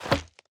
Equip_turtle_shell.ogg.mp3